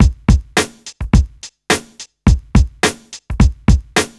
RB106BEAT2-R.wav